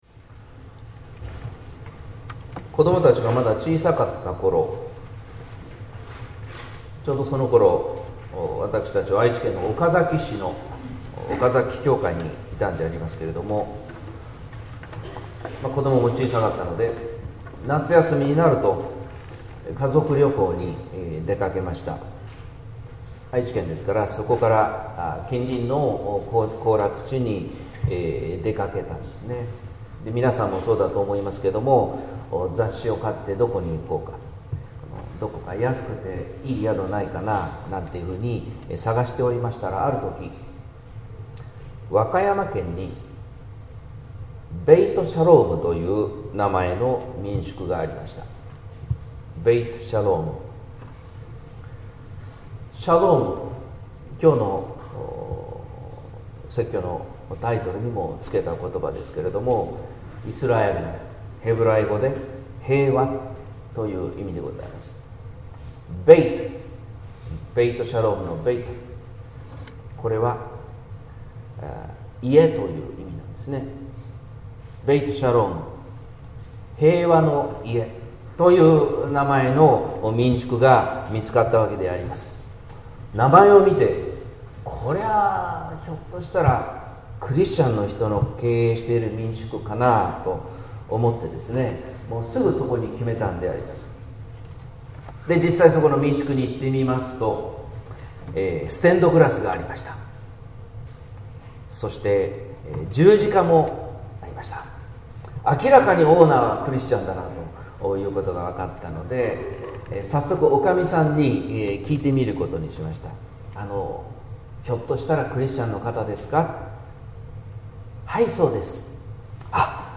説教「夕べのシャローム」（音声版）